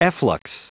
Prononciation du mot efflux en anglais (fichier audio)